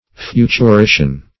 Search Result for " futurition" : The Collaborative International Dictionary of English v.0.48: Futurition \Fu`tu*ri"tion\, n. [Cf. F. futurition.]